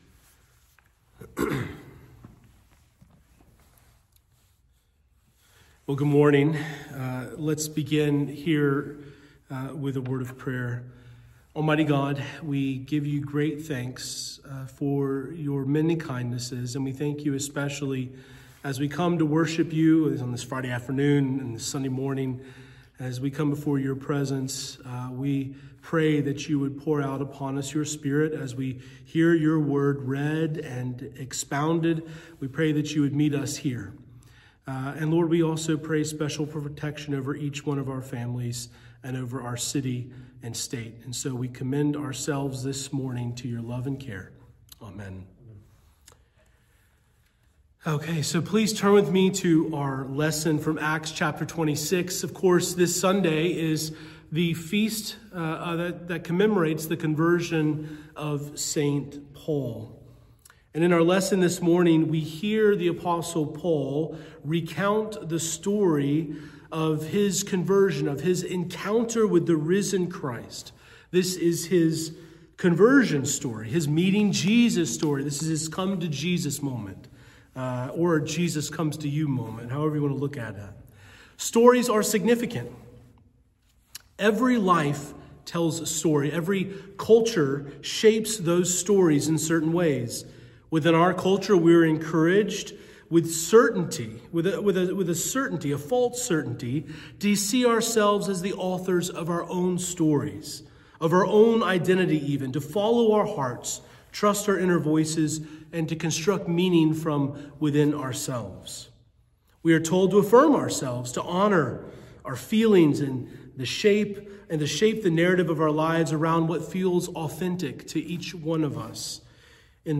Sermon begins at 14:50 minutes in.
Morning Prayer _ The Feast of the Conversion of St. Paul.mp3